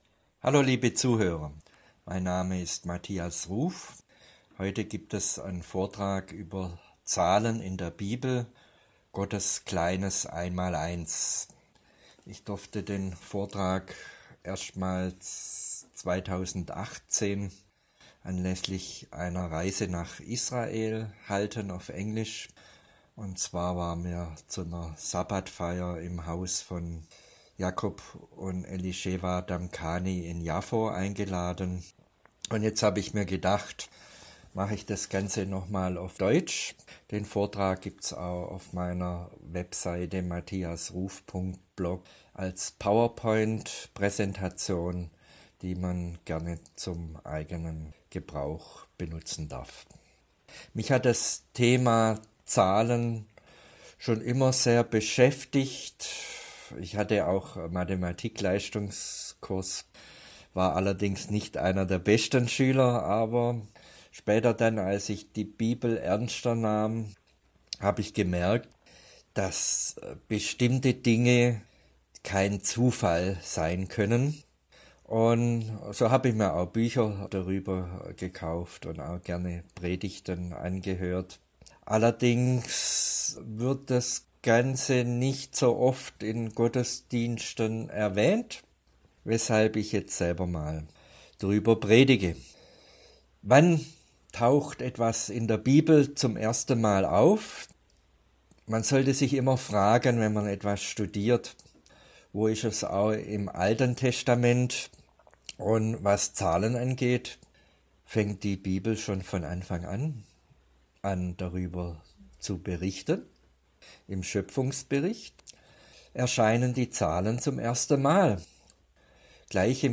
MP3-Sprachaufnahme des Vortrags zum Anhören oder Download: